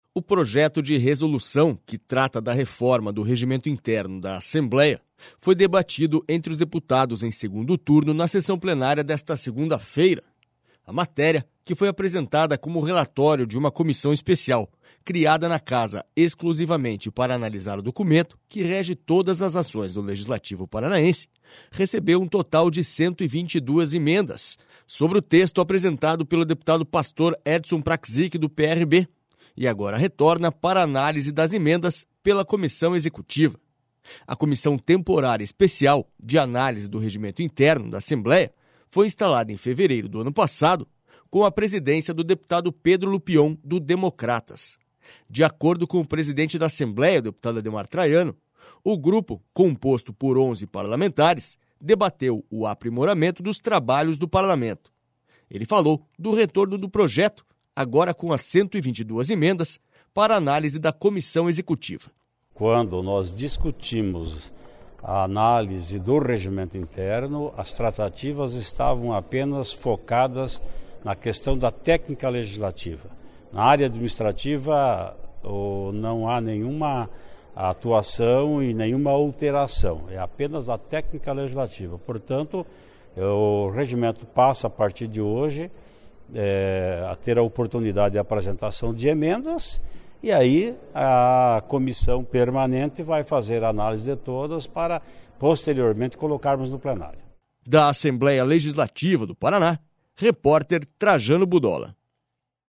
SONORA ADEMAR TRAIANO